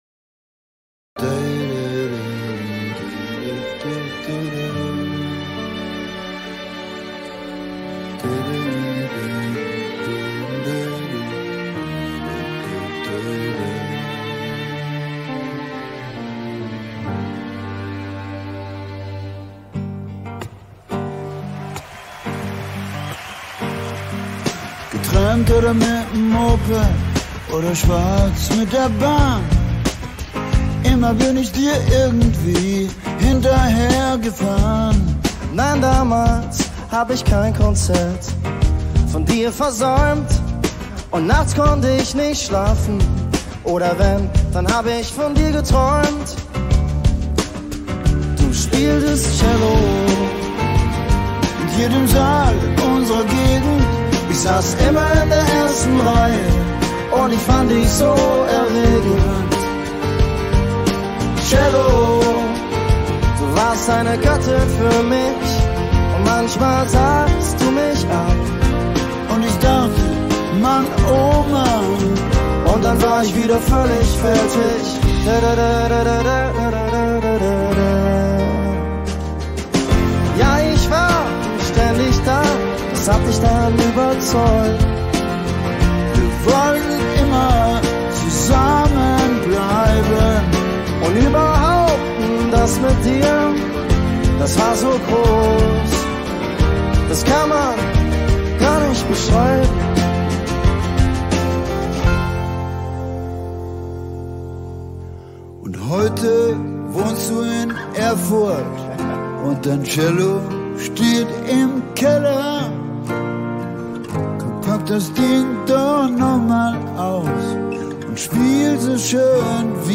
Keys, Programming
Vocals